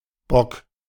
Bock (German: [bɔk]
De-Bock.ogg.mp3